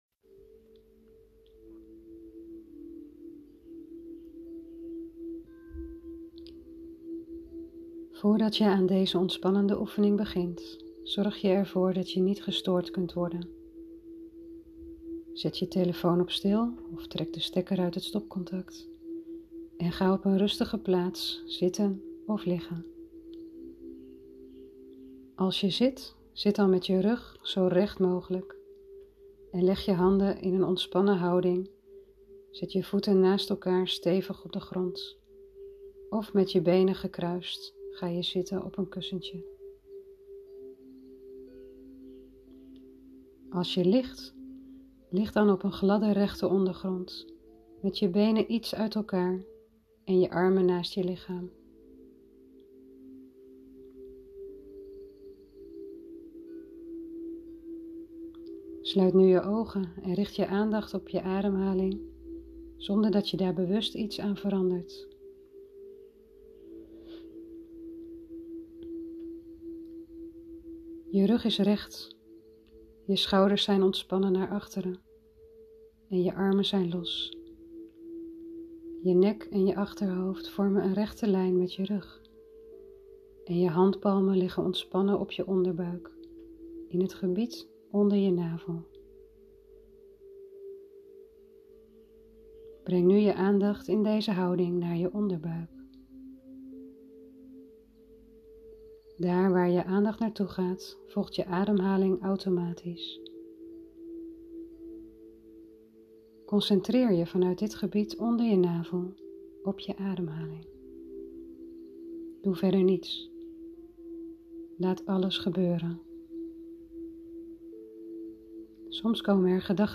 Ook meditatie kan je helpen om de onrust van de dag even achter je te laten. Daarom heb ik vandaag deze meditatie voor jullie opgenomen.
Meditatie